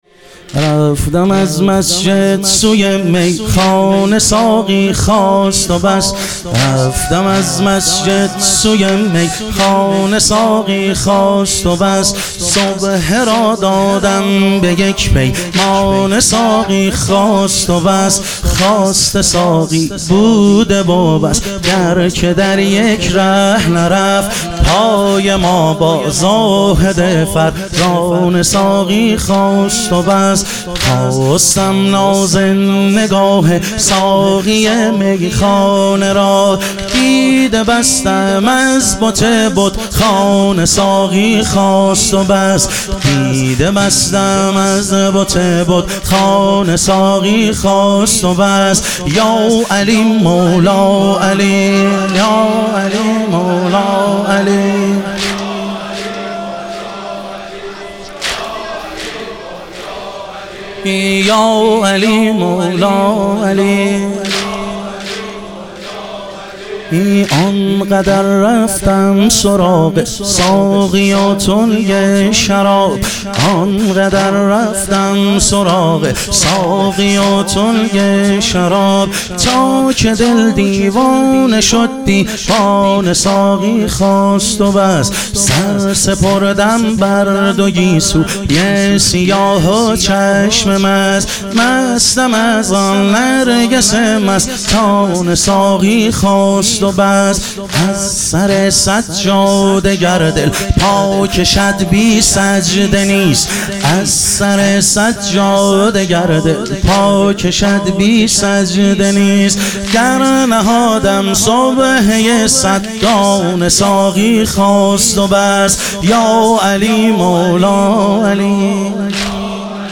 شهادت حضرت سلطانعلی علیه السلام - واحد